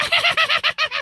goblin.ogg